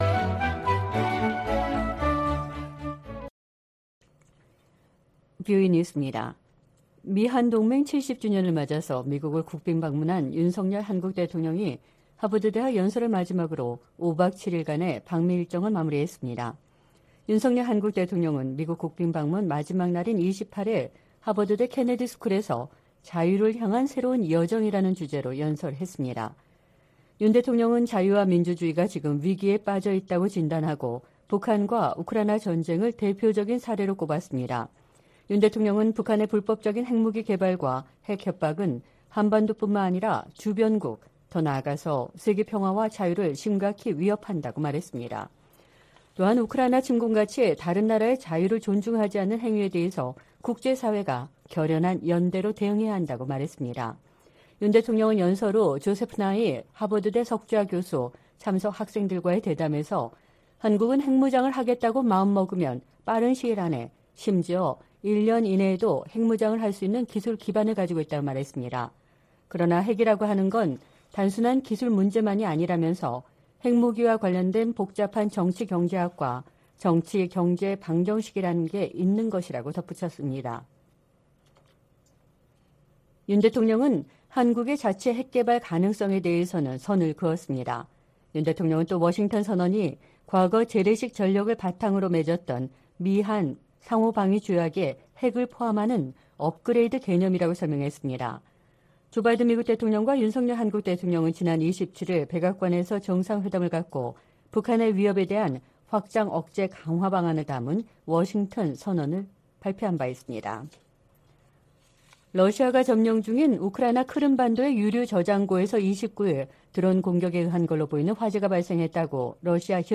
VOA 한국어 방송의 토요일 오후 프로그램 2부입니다.